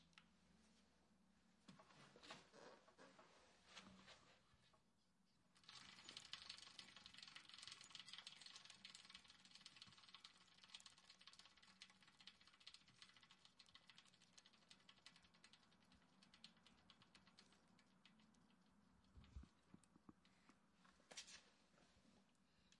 施工现场自行车（正面） 1
描述：施工现场自行车声音环境自然周围的现场录音 环境foley录音和实验声音设计。
Tag: 听起来 自行车 建筑 周边环境 网站 现场记录 自然